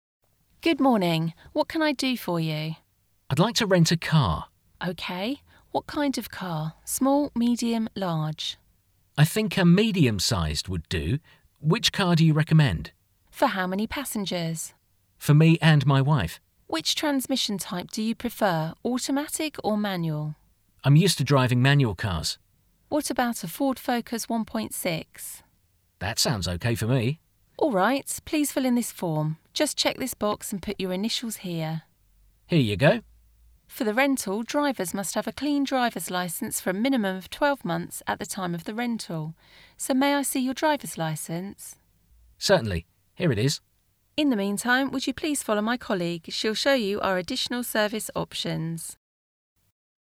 Hasznos párbeszédek – Autóbérlés
Ebből a párbeszédből megtanulhatod az autóbérléssel kapcsolatos legfontosabb kifejezéseket, sőt, a dialógust meg is hallgathatod.